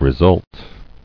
[re·sult]